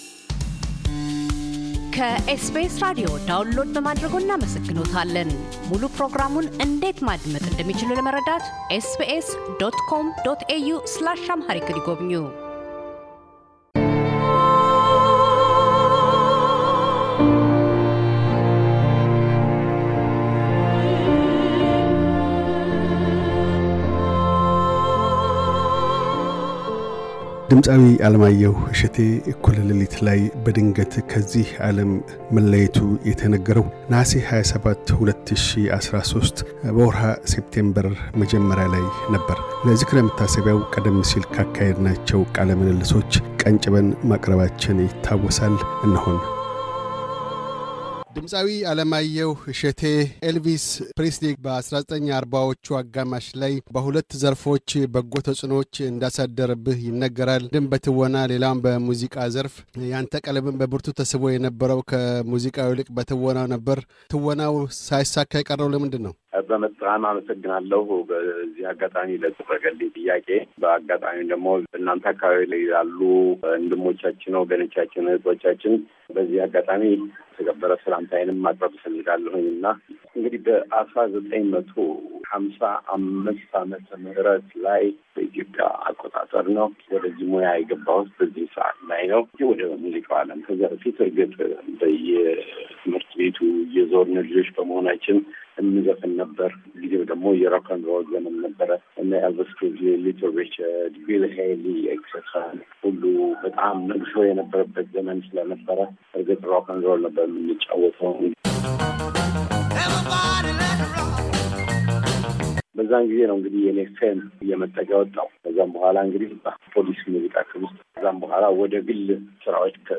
የድምፃዊ ዓለማየሁ እሸቴ እኩለ ለሊት ላይ በድንገት ከዚህ ዓለም መለየት የተነገረው ነሐሴ 27/ 2013 በወርኃ ሴምፕቴምበር 2021 መጀመሪያ ላይ ነው። ለዝክረ መታሰቢያ ቀደም ሲል ካካሄድናቸው ቃለ ምልልሶች ከፊሉን ቀንጭበን አቅርበን ነበር።